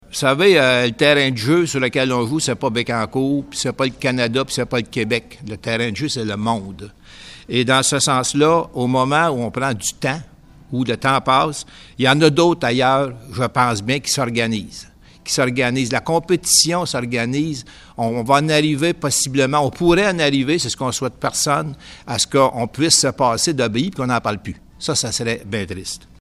En conférence de presse aux côtés de ses collègues, Jean-Guy Dubois a exprimé des craintes au sujet de temps qui passe et qui éloigne les parties d’une solution harmonieuse…